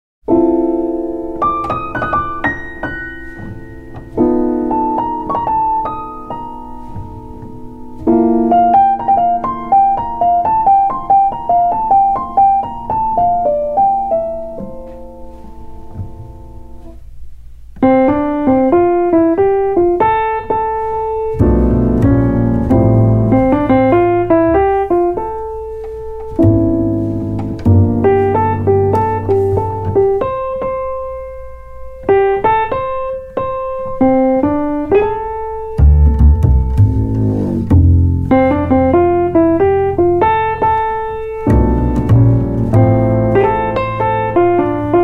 piano
bass
drums
Recorded at Avatar Studio in New York on April 26 & 27, 2010